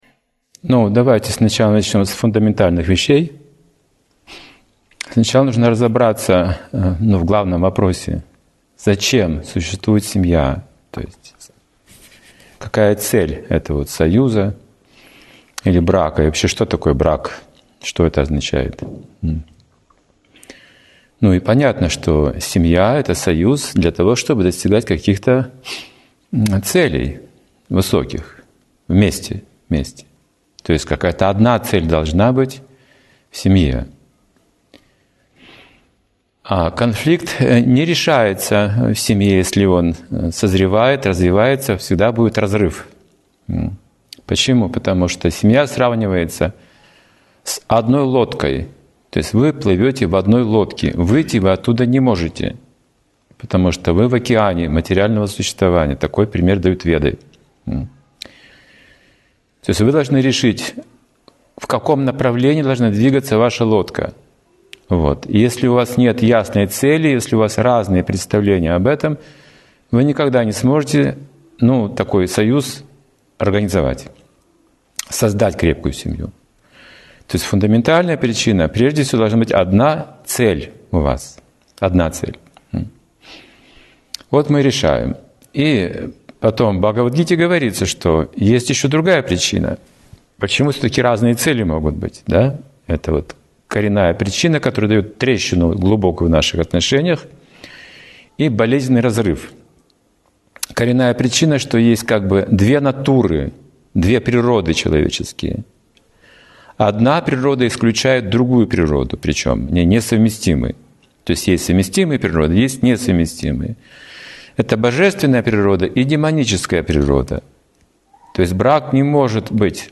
Вопросы и ответы. Как выходить из конфликтной ситуации в семье